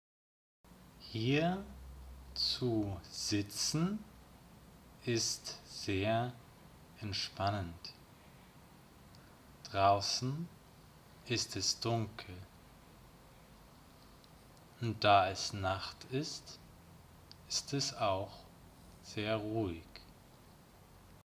Ein ruhiges Video in der Nacht für Deutsch-Anfänger.